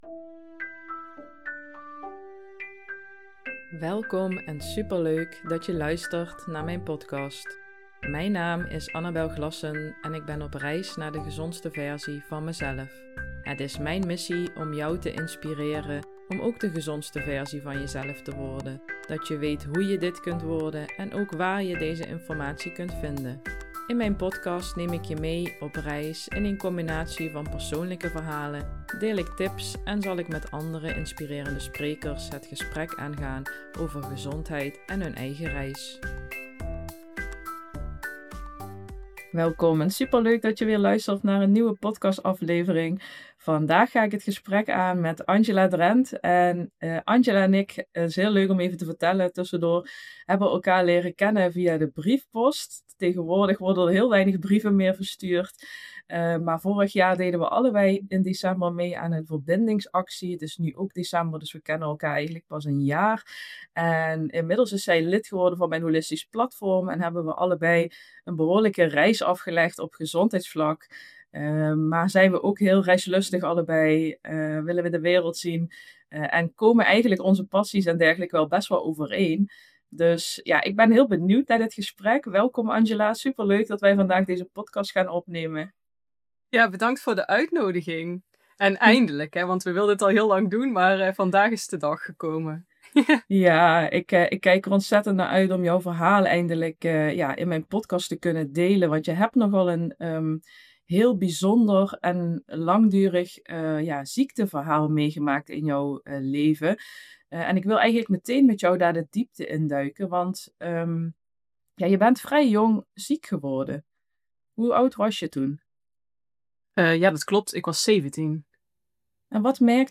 Geen mooie praatjes of snelle oplossingen, maar een eerlijk gesprek over wat er gebeurt wanneer je lichaam je dwingt om te vertragen en naar jezelf te kijken.